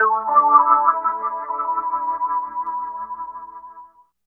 29 GUIT 2 -R.wav